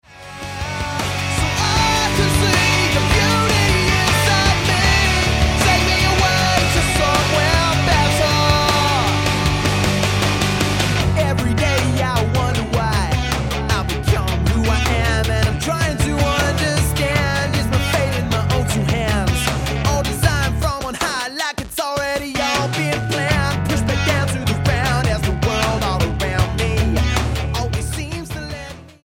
STYLE: Rock
has an insistent groove